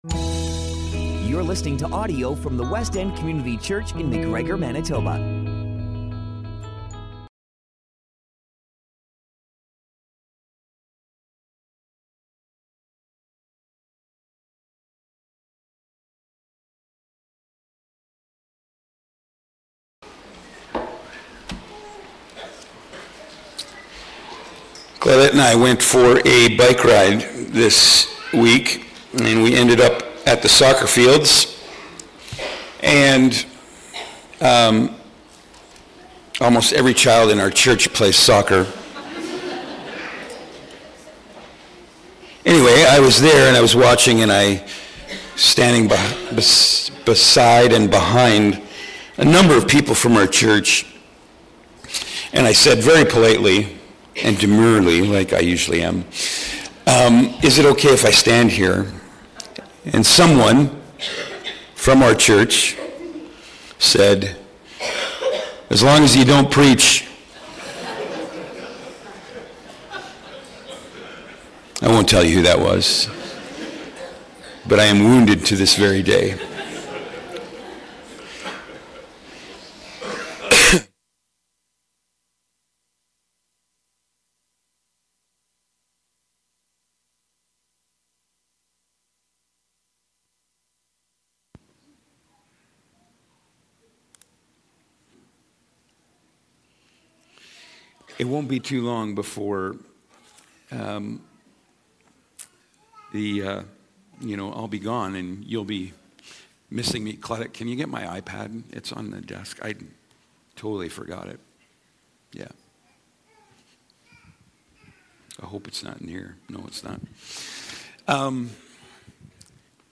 Baptism Sunday - June1, 2025
Sermons